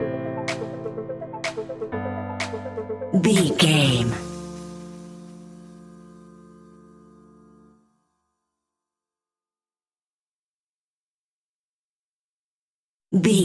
Arp Dance Stinger.
Aeolian/Minor
D
driving
energetic
hypnotic
funky
drum machine
synthesiser
electro
synth leads
synth bass